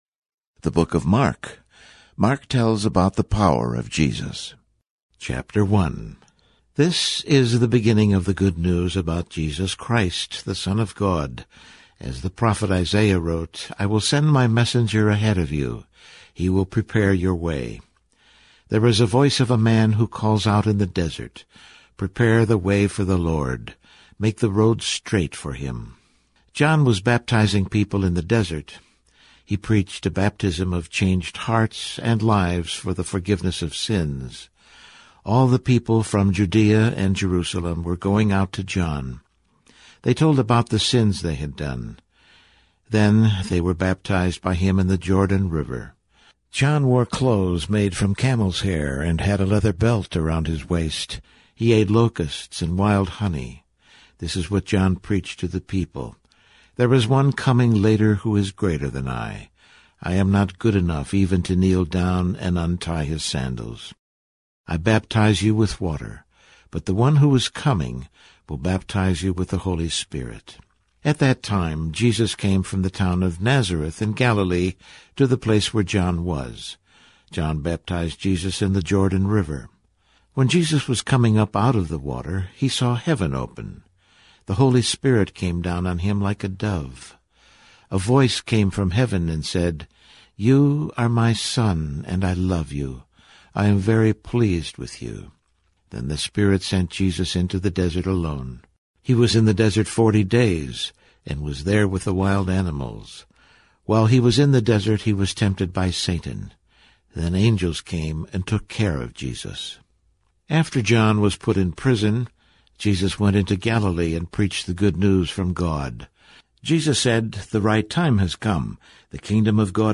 • Word for word narration
• Voice only Bible reading